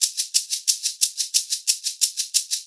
Index of /90_sSampleCDs/USB Soundscan vol.36 - Percussion Loops [AKAI] 1CD/Partition A/10-90SHAKERS